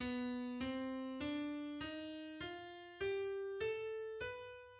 The altered dominant scale built on B